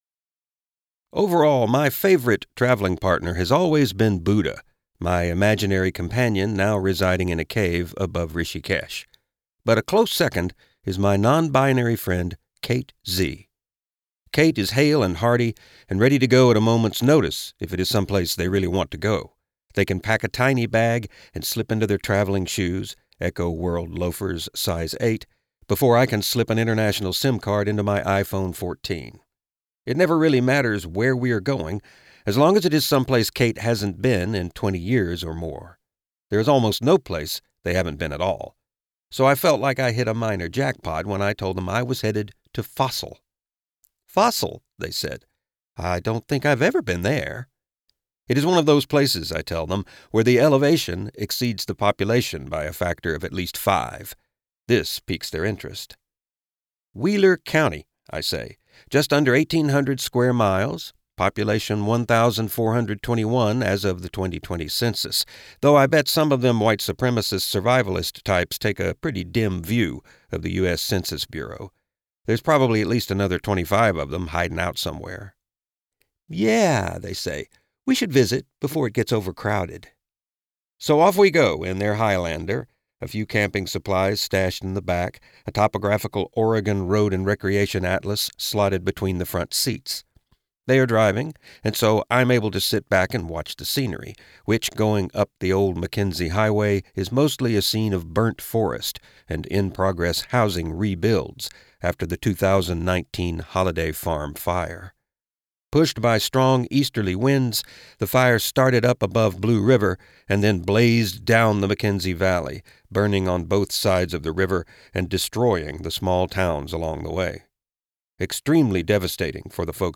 • Audiobook • 08 hrs 58 min